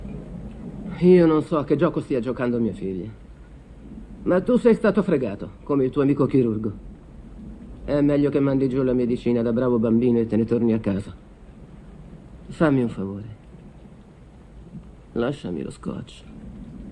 voce di Maria Fiore nel film "Malice - Il sospetto", in cui doppia Anne Bancroft.